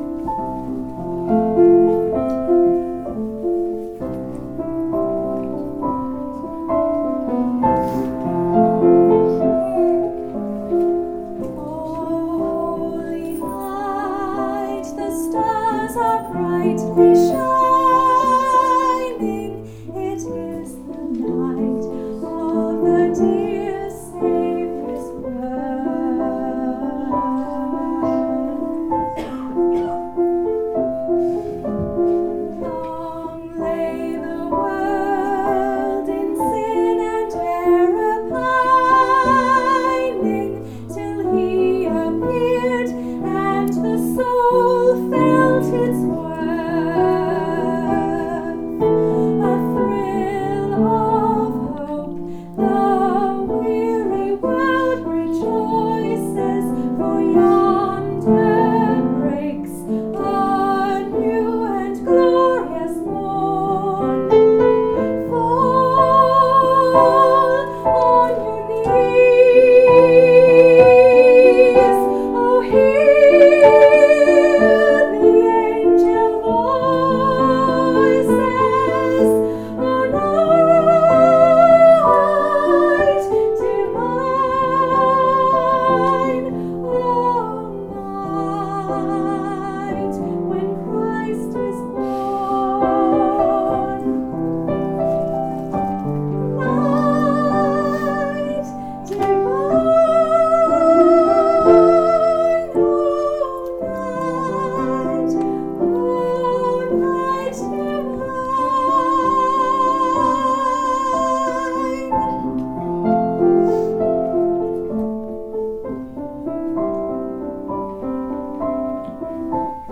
piano
O Holy Night is one of my most favorite Christmas carols. the music was written in 1847 by Adolphe Adam using a French poem by Placide Cappeau. below is my favorite stanza that makes me daydream about what it might have been like to physically walk beside Jesus.